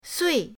sui4.mp3